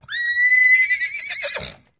Whinnies
Horse Whinnies & Blows 2